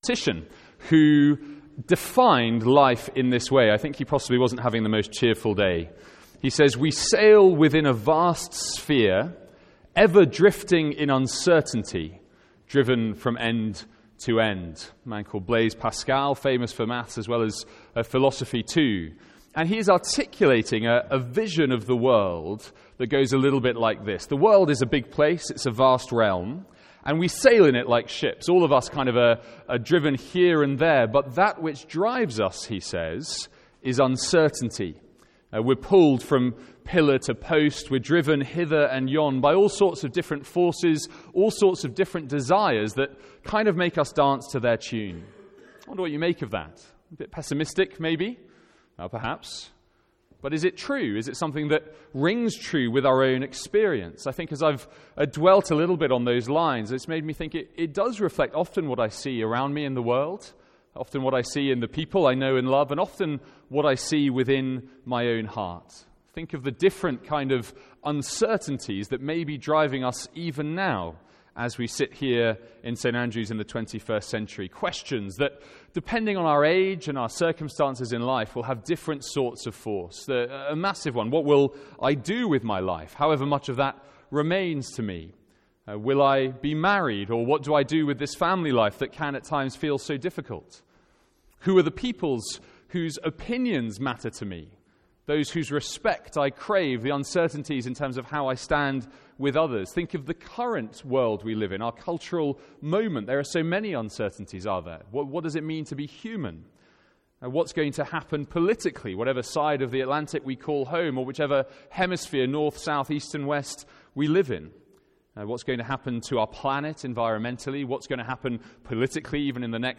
From our Easter Sunday morning service.